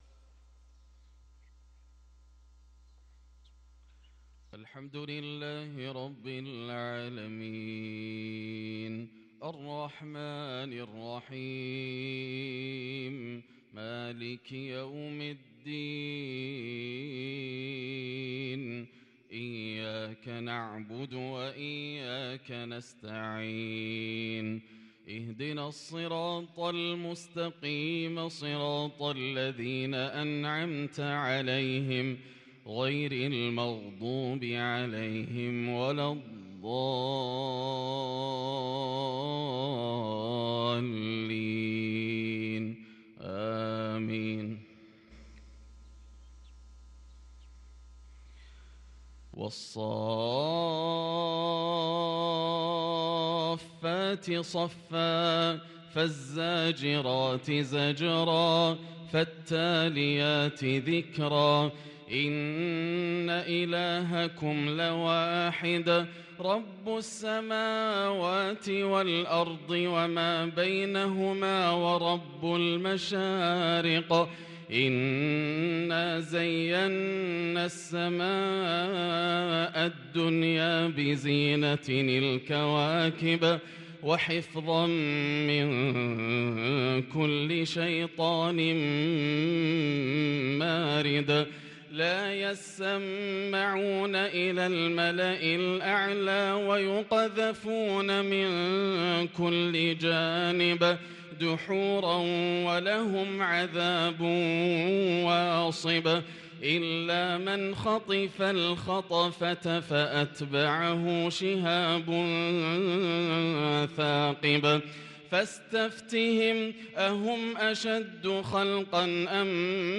صلاة الفجر للقارئ ياسر الدوسري 7 جمادي الآخر 1444 هـ
تِلَاوَات الْحَرَمَيْن .